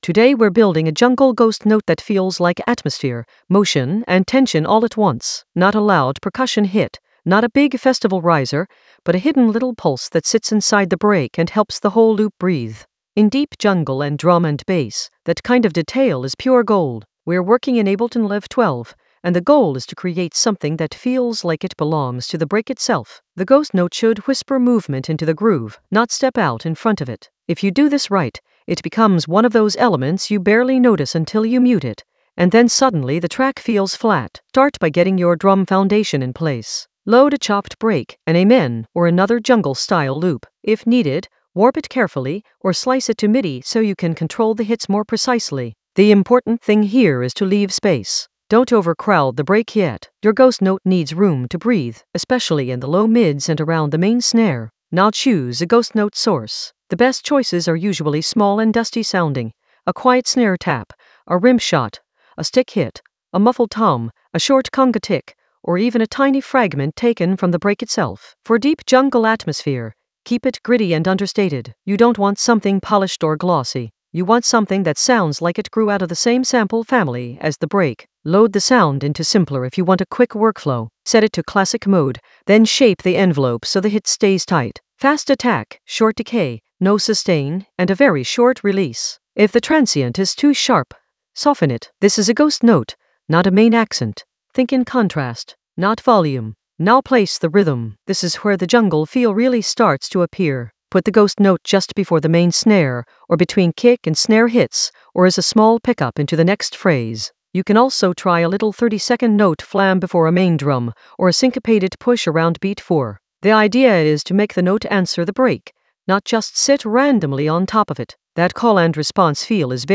An AI-generated intermediate Ableton lesson focused on Blend jungle ghost note for deep jungle atmosphere in Ableton Live 12 in the Risers area of drum and bass production.
Narrated lesson audio
The voice track includes the tutorial plus extra teacher commentary.